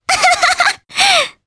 Mirianne-Vox_Happy3_jp.wav